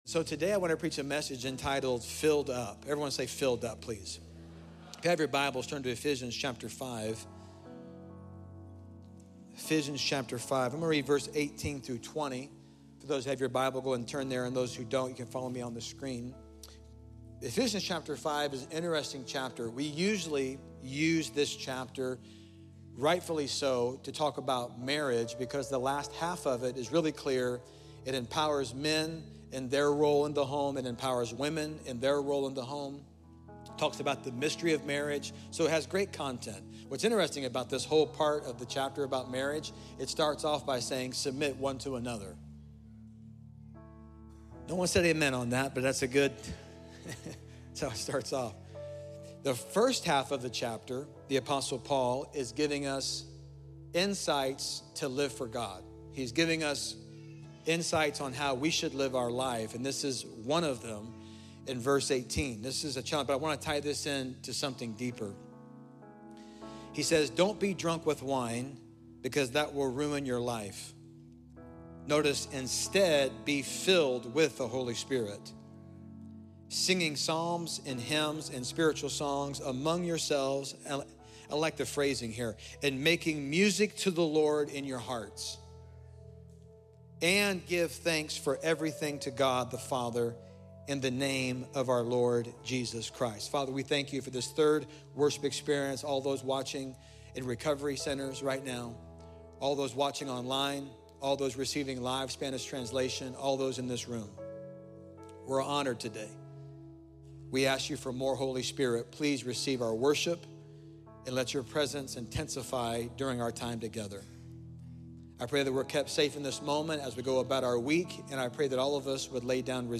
Filled Up by City Church For All Nations: Latest Sermons
Play Rate Listened List Bookmark Get this podcast via API From The Podcast Enjoy powerful messages each week from City Church For All Nations in Bloomington, Indiana.